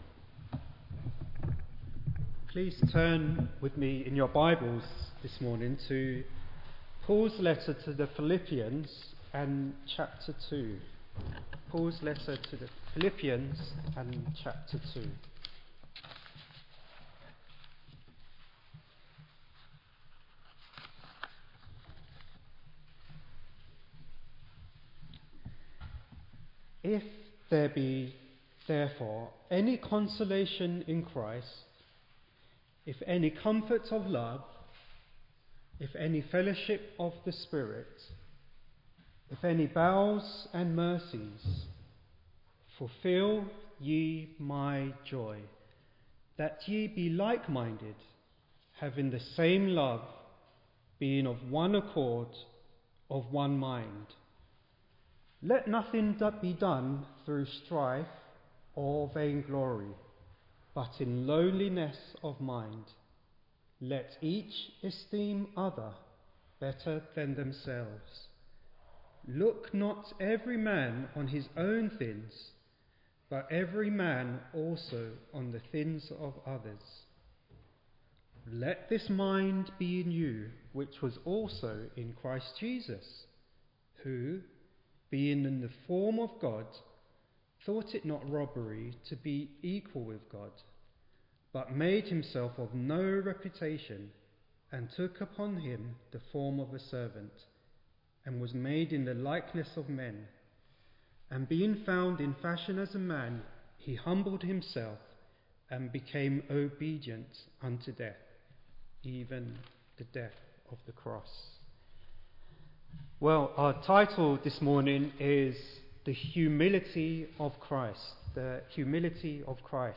Click on the button below to listen to our Recent Sermons.